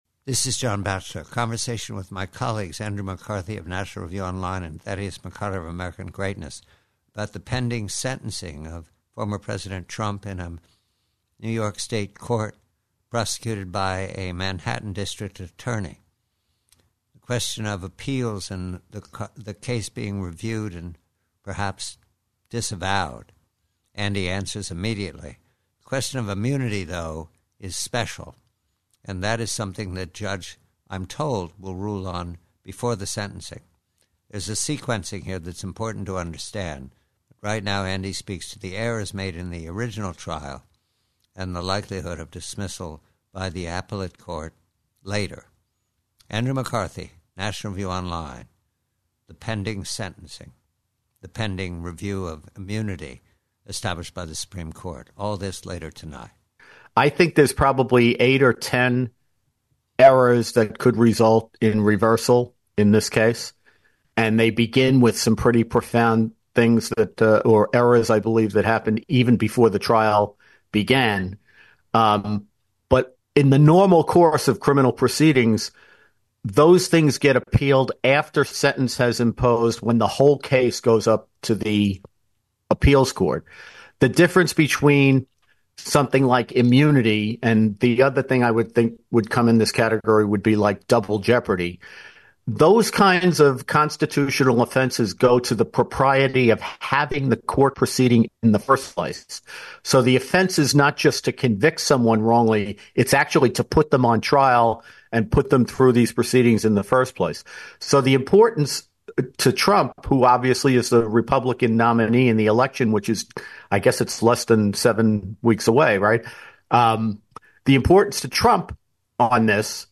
PREVIEW: TRUMP: BRAGG: IMMUNITY: Conversation with colleagues Andrew McCarthy and Thaddeus McCotter re the immunity claim to be ruled on in a New York Court before the sentencing in the same court of former President Trump.